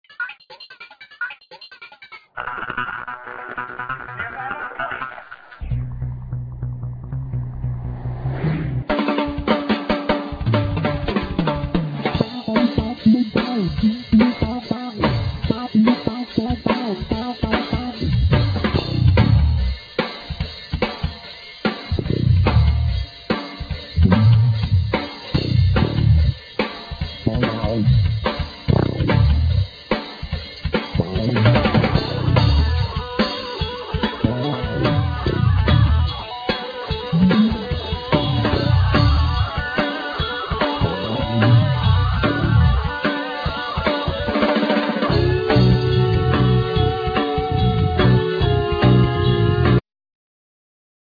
Bass
Keyboards
Trumpet
Guitar
Drums
Percussions
Trombone